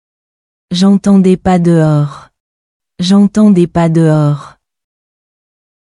これはほぼ同じに聞こえますが、実は J’entendais の [ais] と　des の [es] は違う音です。
J’entendais pas dehors.　  [ʒ‿ɑ̃.tɑ̃. pa də.ɔʁ]　ɛ =　口を少し縦に開いた [エ]
J’entends des pas dehors.  [ʒ‿ɑ̃.tɑ̃ de pa də.ɔʁ]  e  = 口を横にひっぱった [エ]
しかも、イントネーションが若干違います。
確かに文章のイントネーションは違いますが、[エ] のところはそっくりですね....。注意して聞かないと、ほとんど分かりません。